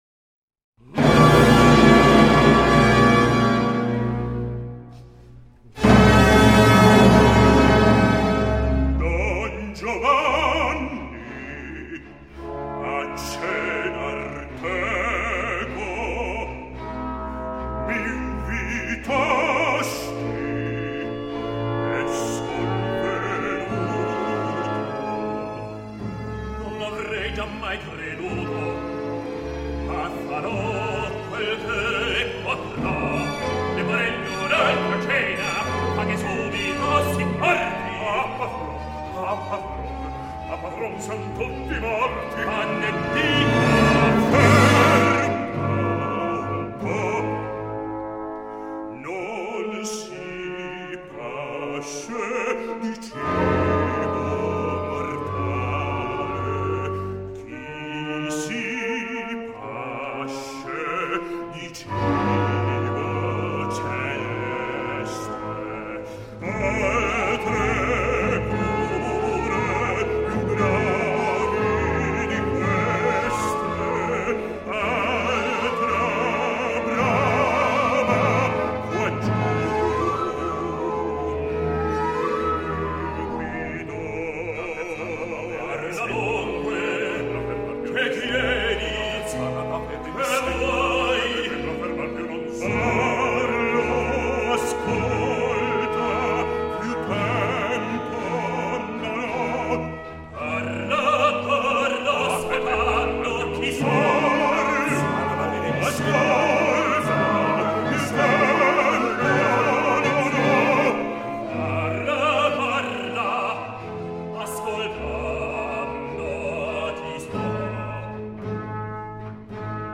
Χαρακτηριστικό δείγμα του ρεύματος Sturm und Drang (καθώς και ελάχιστο δείγμα της καταπληκτικής ικανότητας του Mozart να παρουσιάζει ταυτόχρονα χαρακτήρες σε διαφορετική κατάσταση – εδώ, μπροστά στην απειλητική παρουσία από τον άλλο κόσμο, παράλληλα με τον ιταμό DG ο τρομοκρατημένος υπηρέτης του). Ο DG είχε προσπαθήσει να αποπλανήσει μεταξύ άλλων τη Δόνα Άννα, καυχιόταν και προκαλούσε μπροστά στον τάφο του επιφανούς πατέρα της, και το βράδυ του χτυπά την πόρτα το άγαλμά του: τον καλεί να μετανοήσει, αυτός αρνείται πεισματικά και τον καταπίνει η κόλαση.
Διευθύνει ο René Jacobs.